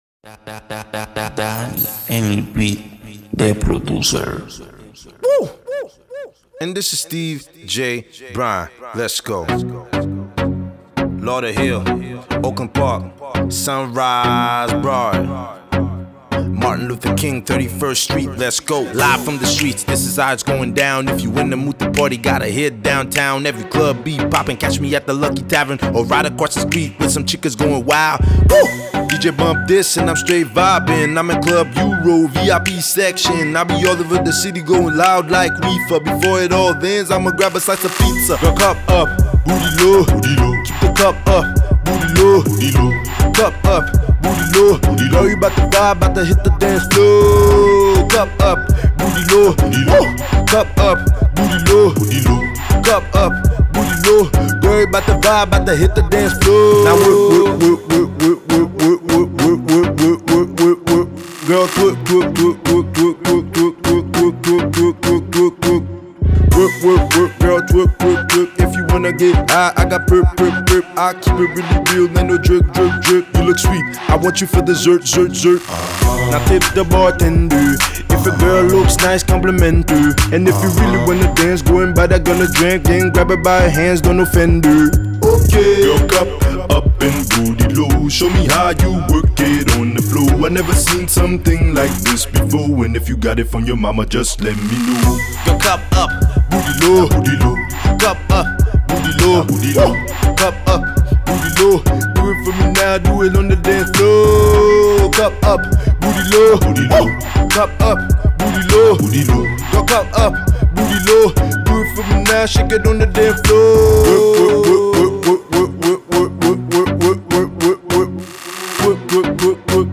Hey guys, I'm new on the forum and really appreciate the versatility as well as the infos provided on here. I just received this song last night and although it wasn't the most cleaned recording, I tried to make the best out of it.